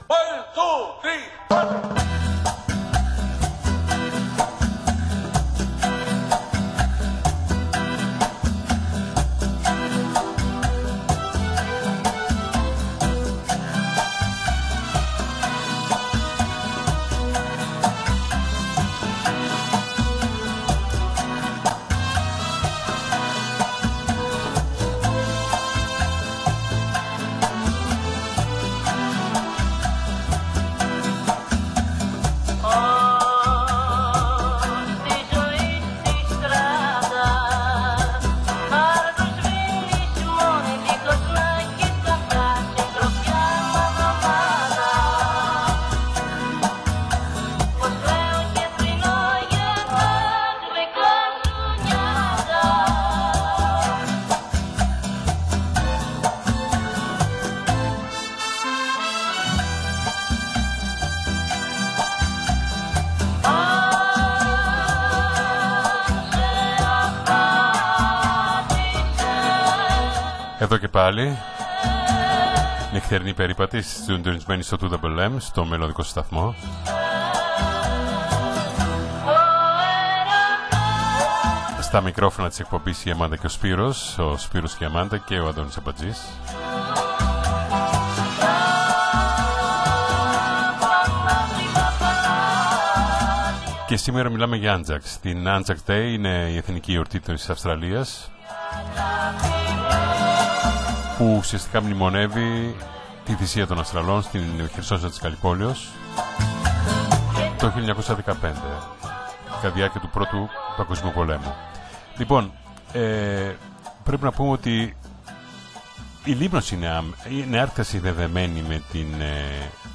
στην διάρκεια της επετειακής εκπομπής ” Νυκτερινοί Περίπατοι” της Πέμπτης 25/04/2024 του ελληνόφωνου ραδιοφωνικού σταθμού του Σίνδει https